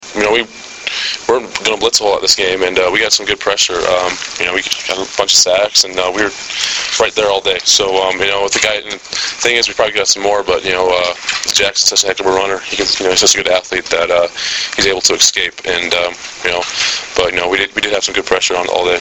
Below are links to audio segments of interviews done with Husker players and head coach Bill Callahan after Nebraska's 37-14 win over Colorado.